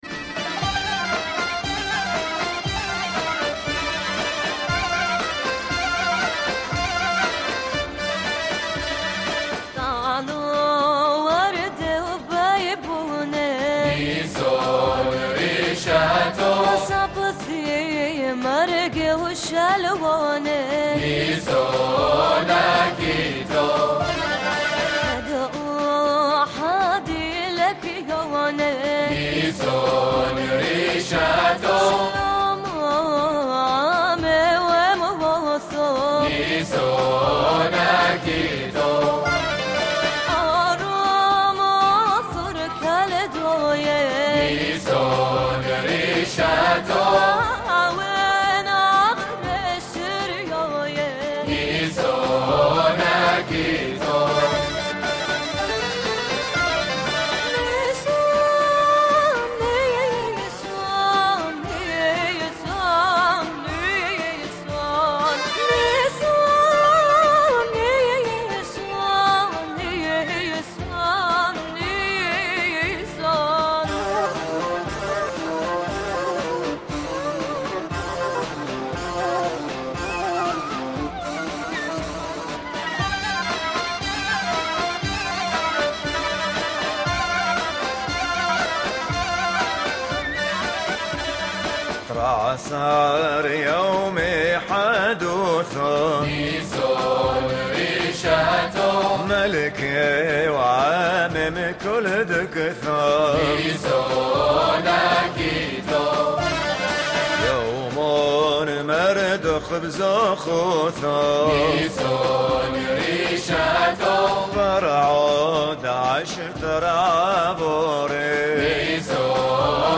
Folklore